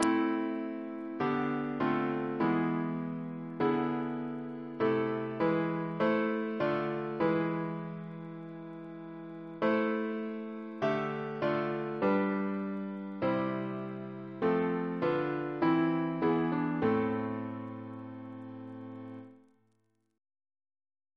CCP: Chant sampler
Double chant in A minor Composer: Sir Joseph Barnby (1838-1896), Precentor of Eton, Principal of the Guildhall School of Music Reference psalters: ACB: 150; ACP: 66; CWP: 75; RSCM: 15